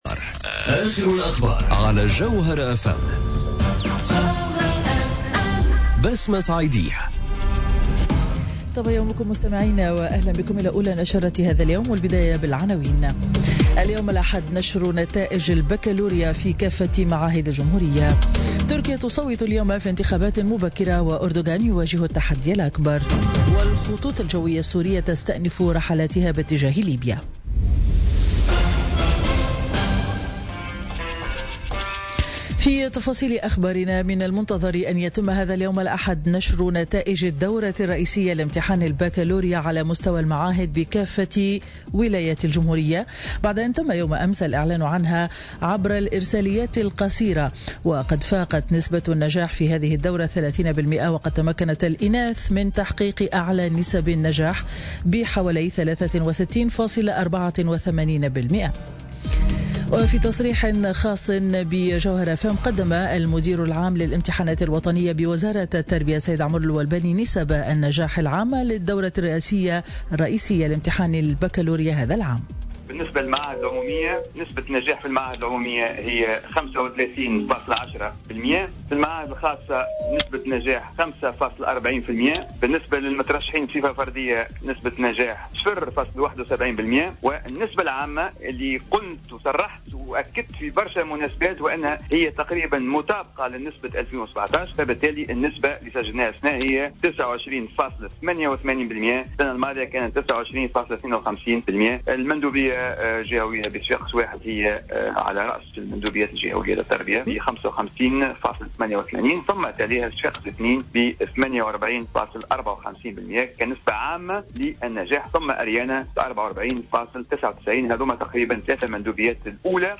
Journal Info 07h00 du dimanche 24 Juin 2018